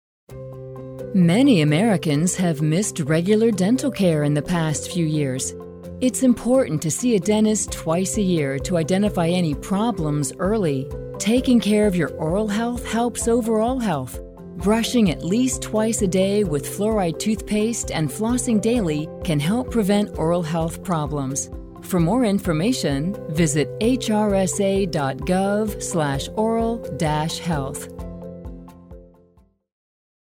Recorded radio PSA
oral-health-radio-psa.mp3